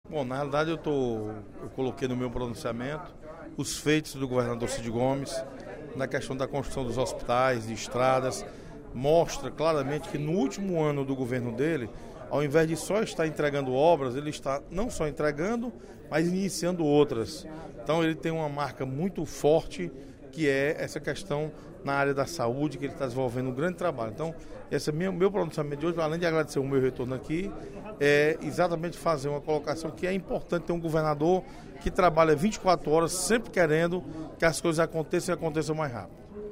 No primeiro expediente da sessão plenária desta terça-feira (20/05), o deputado Osmar Baquit (PSD) agradeceu o atendimento recebido após sofrer um acidente automobilístico na BR 116, no dia 10 de maio. O parlamentar destacou ainda as ações do Governo do Estado na área da saúde.